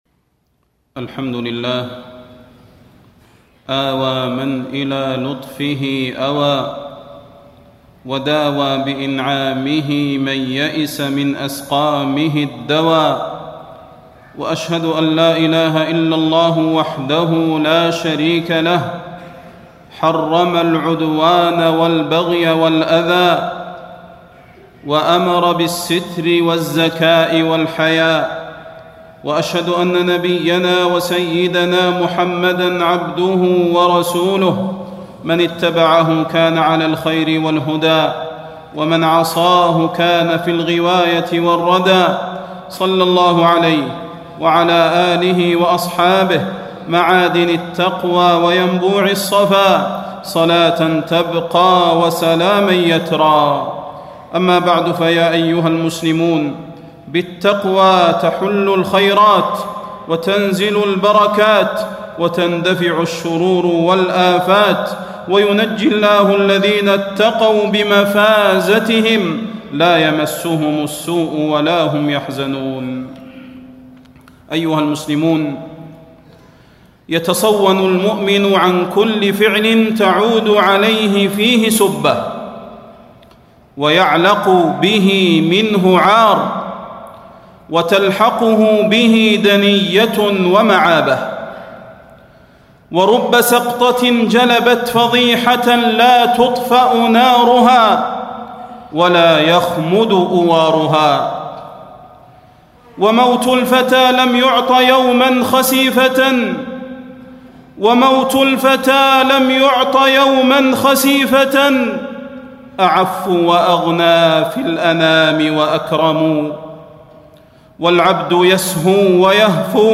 تاريخ النشر ١٢ صفر ١٤٣٣ هـ المكان: المسجد النبوي الشيخ: فضيلة الشيخ د. صلاح بن محمد البدير فضيلة الشيخ د. صلاح بن محمد البدير خطر الجهر بالمعاصي والذنوب